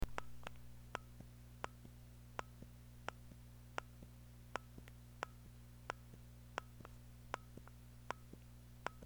Hear are some short audio sound bytes / clips (all recordings were done at the same record volume level and same distance away from the microphone) of a stock Atari CX24 fire button being pressed and released over and over.
Stock CX24 Fire button MP3 sound byte
You will hear the stock CX24 fire button sound has a muffled sound with a single click sound.
Stock CX24 fire button sound.mp3